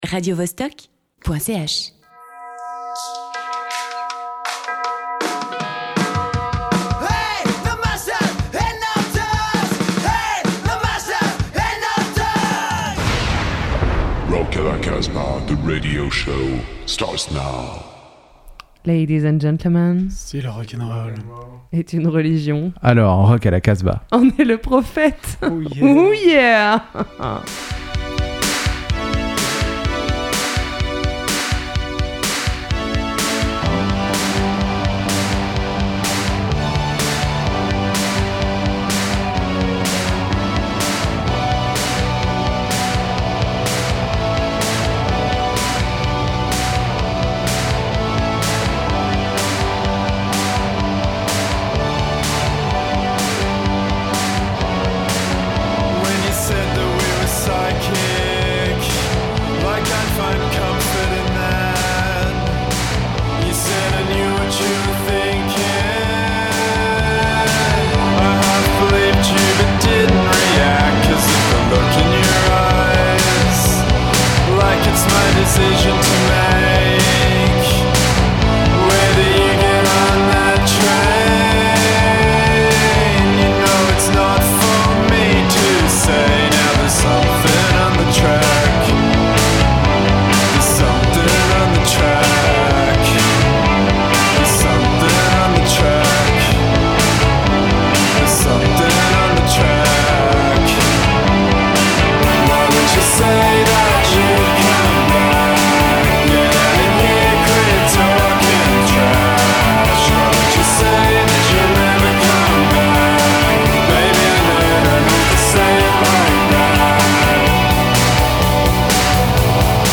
Rock à la Casbah est réalisée par six fans de rock’n’roll basés à Valence dans la Drôme. C’est une émission de rock underground qui laisse la part belle aux scènes émergentes rock, garage, blues, punk.